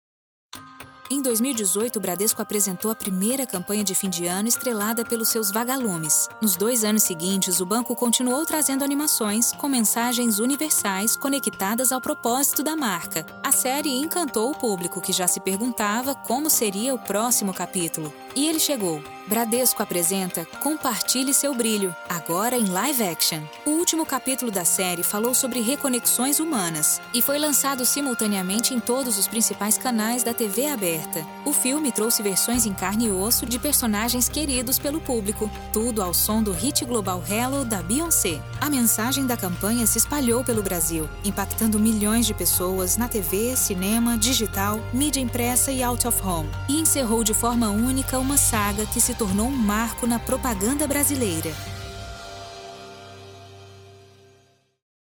Natural, Reliable, Friendly, Soft, Corporate
Corporate